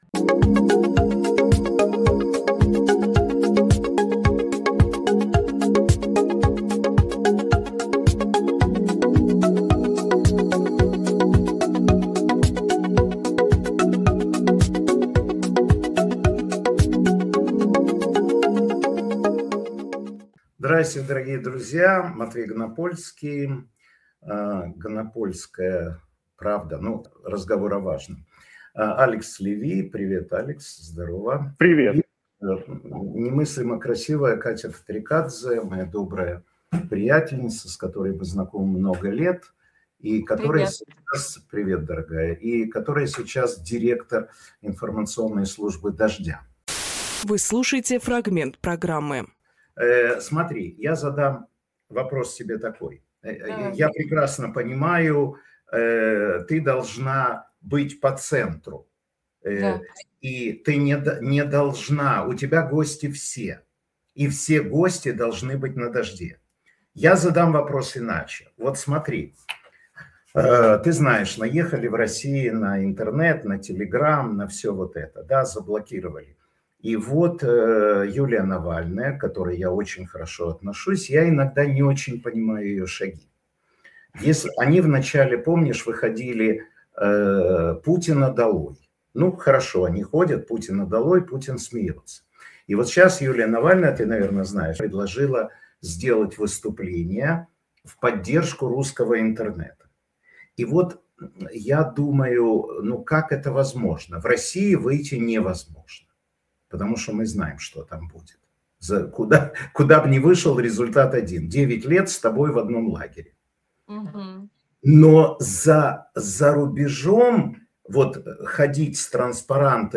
Фрагмент эфира от 13.02.26